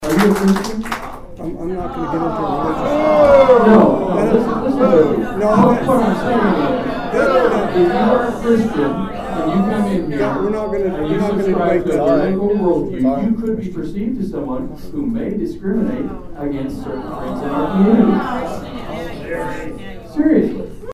loud boos from the public.
After Sherrick continued his points on religion and political beliefs, Curd forcefully responded he is there to represent the citizens regardless of personal beliefs and political affiliation.